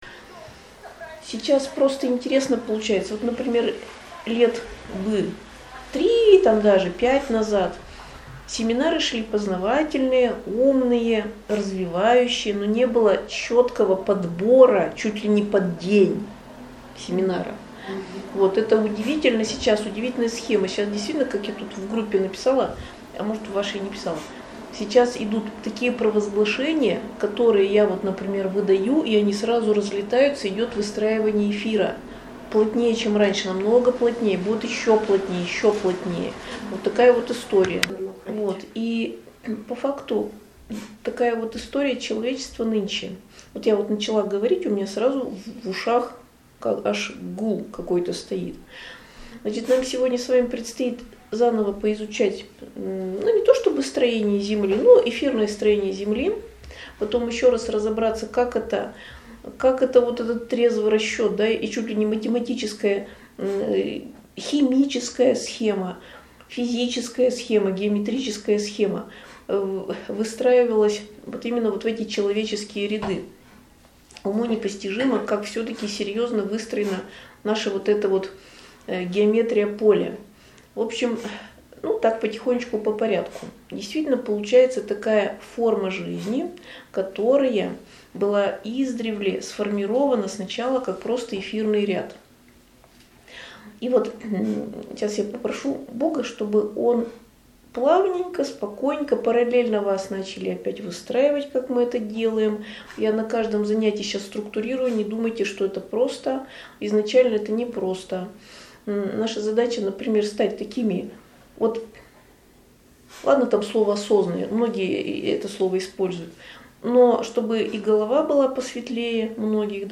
Аудиозапись семинара в Северодвинске, 12.10.23 Скачать